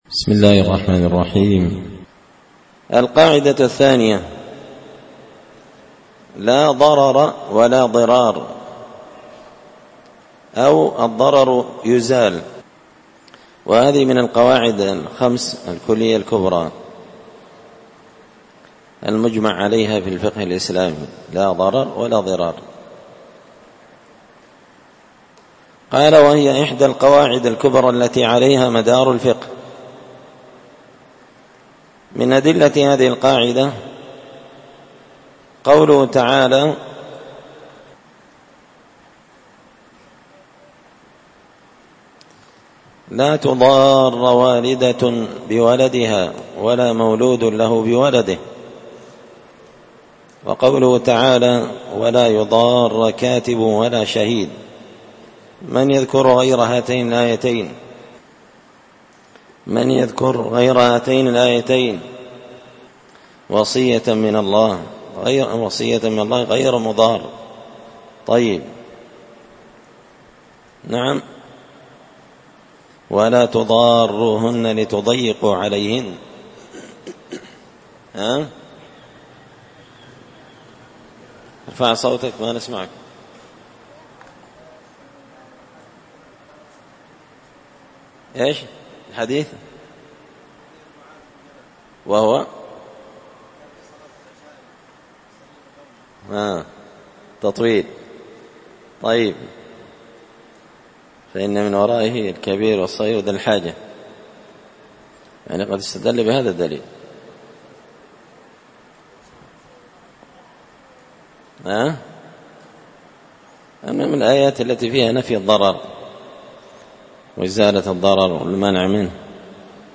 تسهيل الوصول إلى فهم منظومة القواعد والأصول ـ الدرس 6
دار الحديث بمسجد الفرقان ـ قشن ـ المهرة ـ اليمن